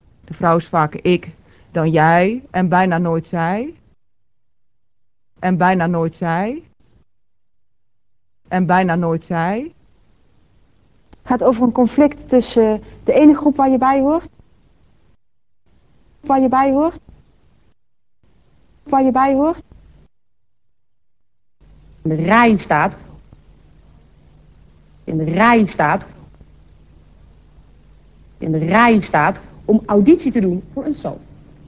Ter illustratie dient bijgaand geluidsbestand dat korte spraakfragmenten bevat van achtereenvolgens een universitair docent, een hoogleraar (beide zijn 40-ers en resp. afkomstig uit Overijsel en Zuid-Limburg) en een presentatrice van RTL 4, die in de dertig is en uit de Randstad komt.